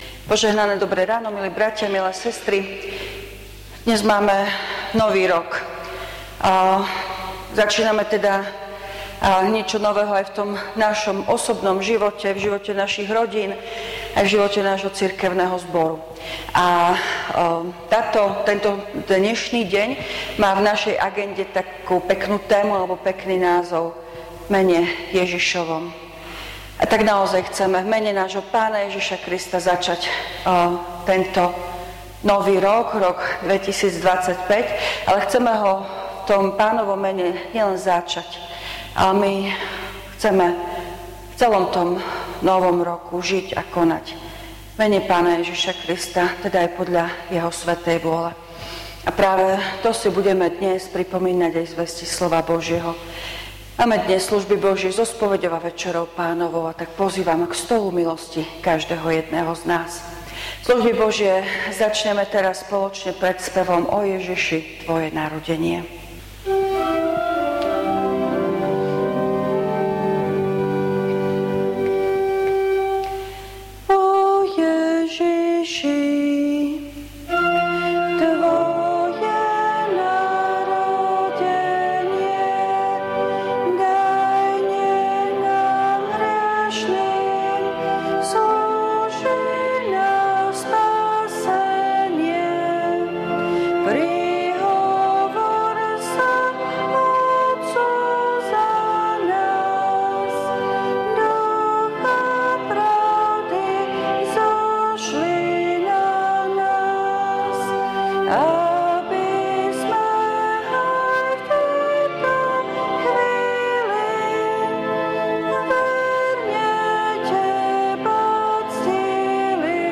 Služby Božie – Nový rok
V nasledovnom článku si môžete vypočuť zvukový záznam zo služieb Božích – Nový rok.